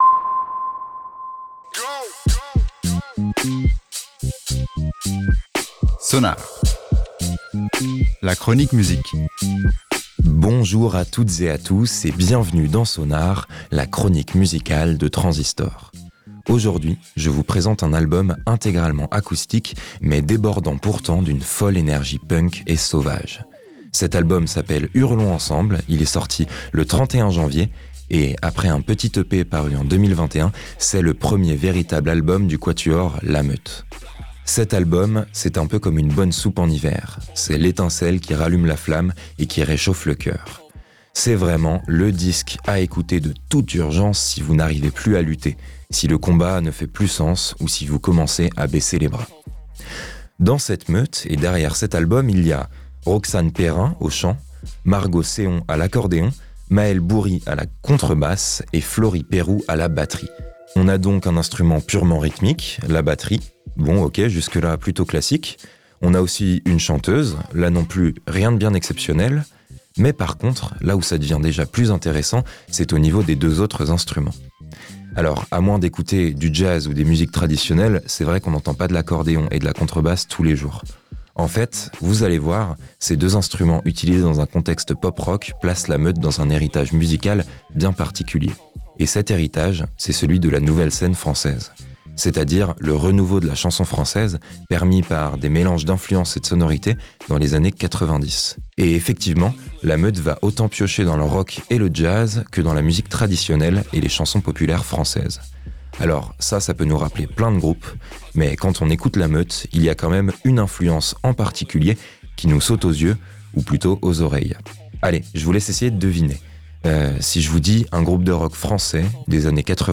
Sans oublier de mentionner les coeurs qu'elles réalisent toutes, le coktail est explosif : un mélange acoustique et organique de rock jazzy et de chanson traditionelle française.